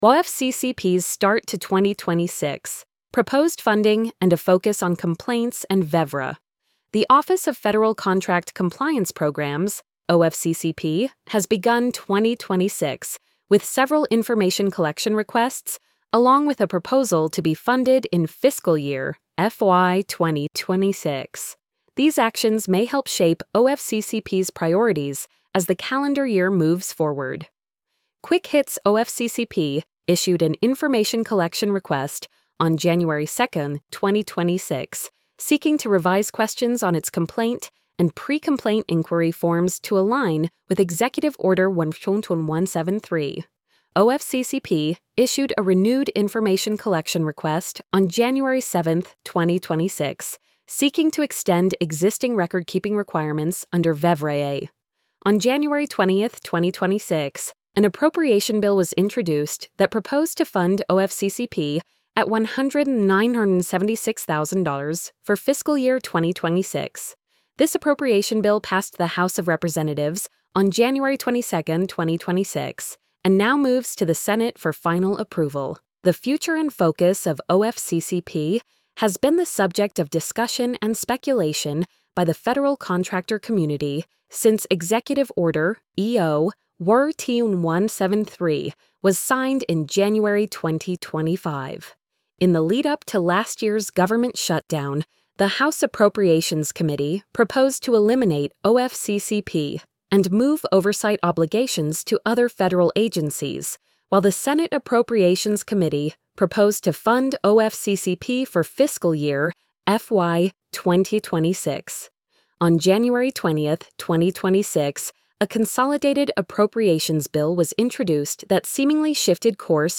ofccps-start-to-2026-proposed-funding-and-a-focus-on-complaints-and-vevraa-tts.mp3